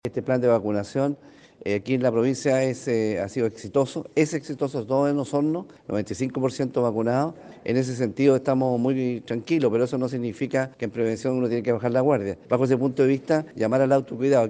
Asimismo, el gobernador de Osorno, Daniel Lilayú señaló que a pesar de las buenas cifras a nivel local, »uno no puede bajar la guardia».